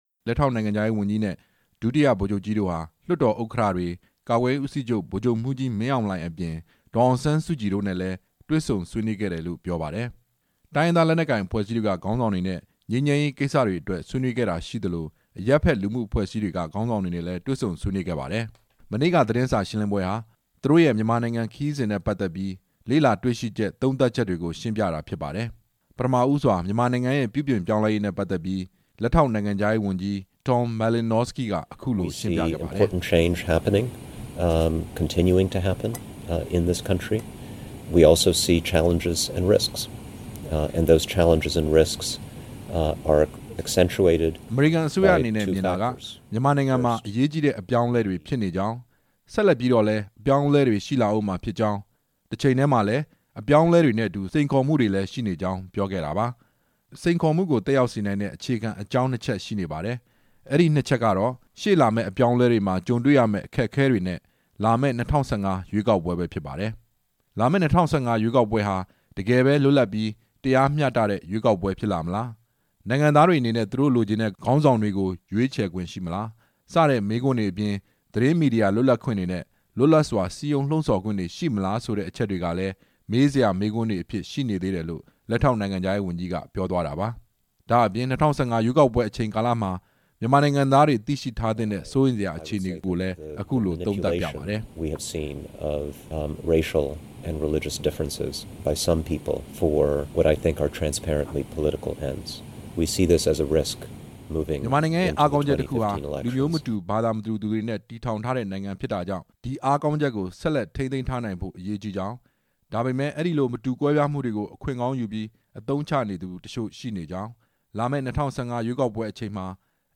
ရန်ကုန်မြို့ အမေရိကန်သံရုံးမှာ မနေ့က ကျင်းပတဲ့ သတင်းစာရှင်းလင်းပွဲမှာ လက်ရှိအချိန်ဟာ စစ်တပ်ချင်း ဆက်ဆံရေး တည်ဆောက်ဖို့ အချိန်ရောက်ပြီလားလို့ သတင်းထောက်တစ်ဦးက မေးမြန်းရာမှာ အမေရိကန် အစိုးရ ဒီမိုကရေစီ လူ့အခွင့်အရေး နဲ့ အလုပ်သမားရေးရာ လက်ထောက်နိုင်ငံခြားရေးဝန်ကြီး Tom Malinowski က အခုလို ရှင်းပြခဲ့တာပါ။